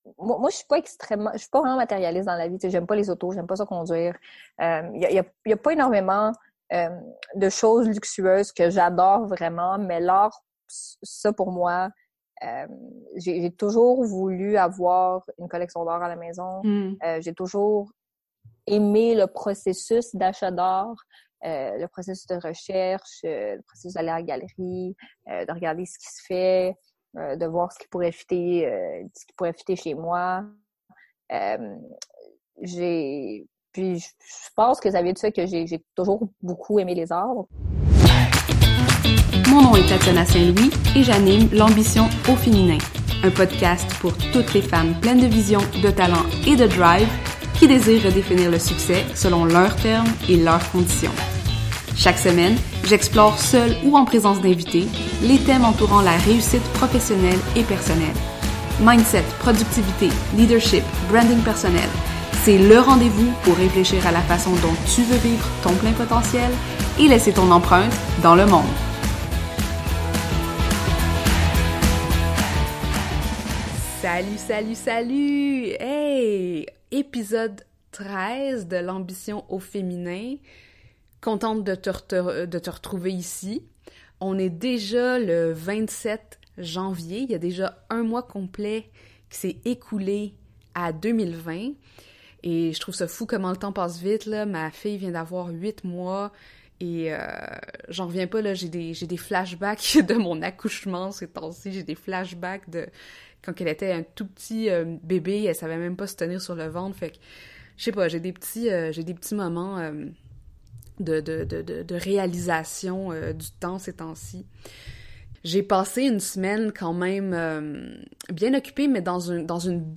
Dans la deuxième partie de l’entrevue, on discute donc de : L’importance de l’équilibre travail et vie personnelle Sa passion pour l’art et si elle aimerait en faire plus qu’un hobby La relation qu’elle voit entre argent et succès Son plan sur 5 ans, ses bilans et listes de souh